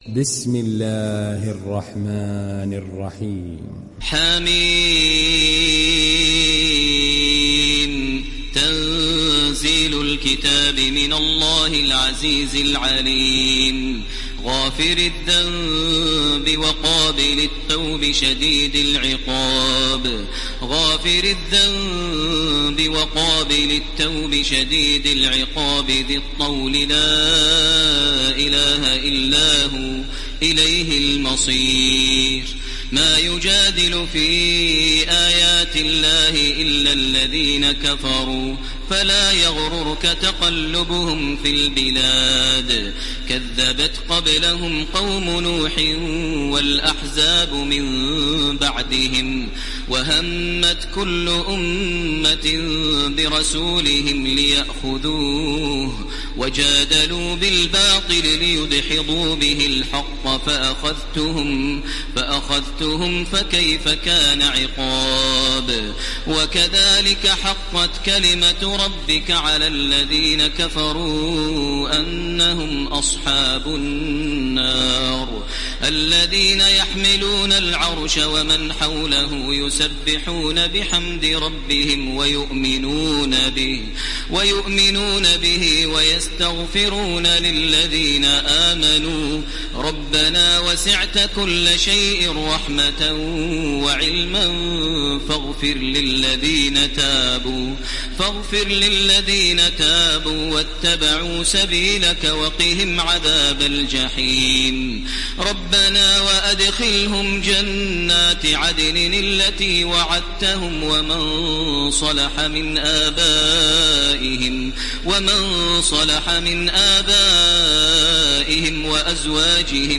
دانلود سوره غافر تراويح الحرم المكي 1430